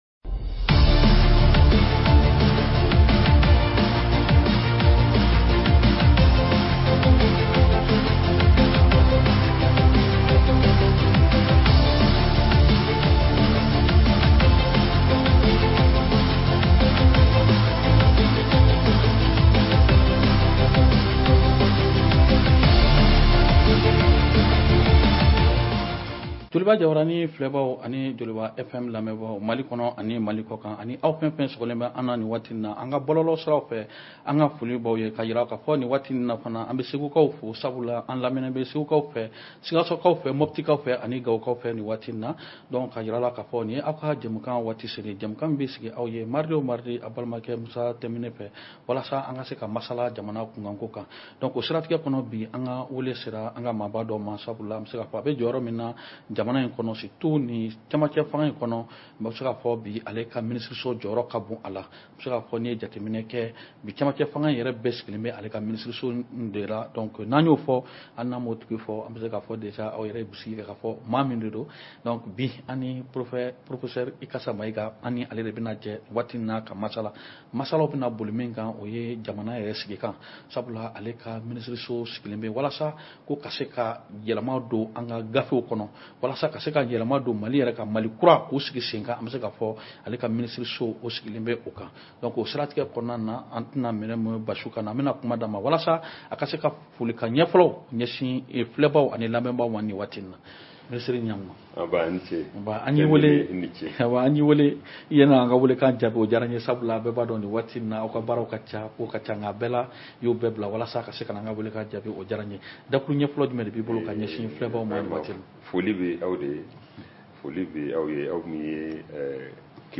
Notre invité est Ibrahim Ikassa MAÏGA,Ministre de la refondation du Mali.